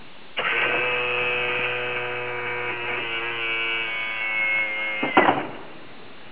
gear-dn.wav